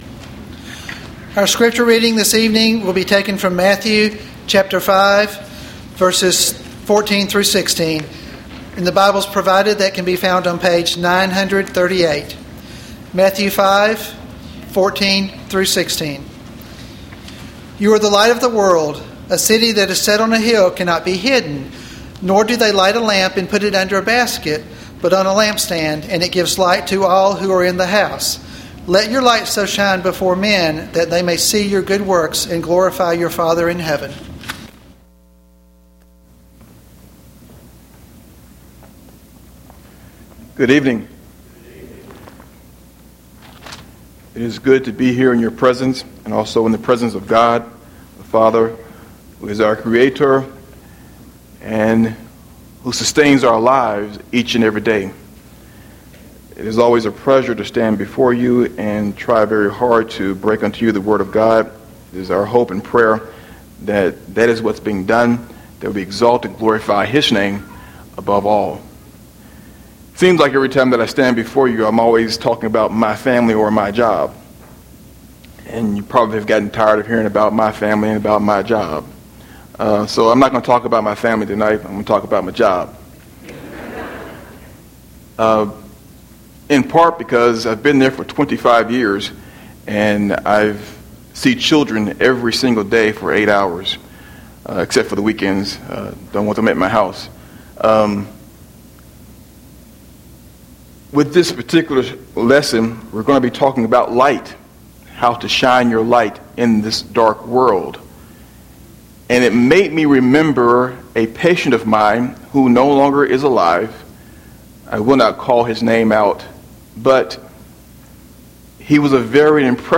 AM Worship